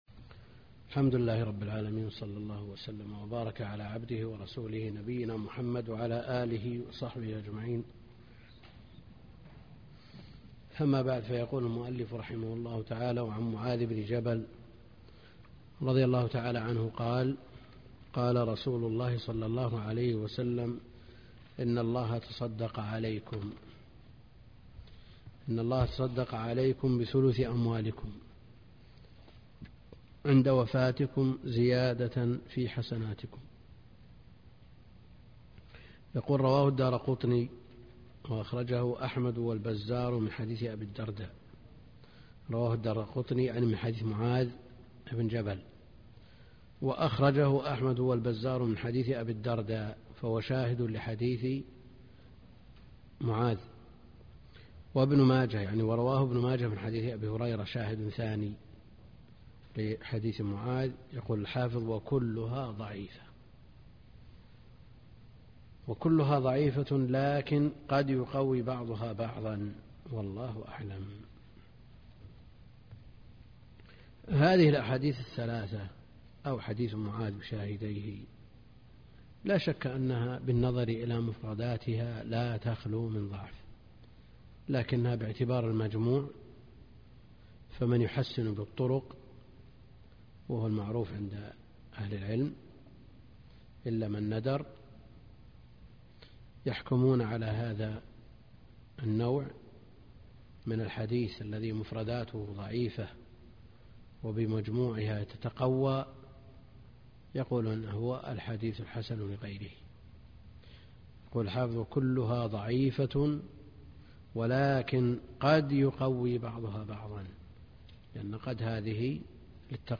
الدرس (25) كتاب البيوع من بلوغ المرام - الدكتور عبد الكريم الخضير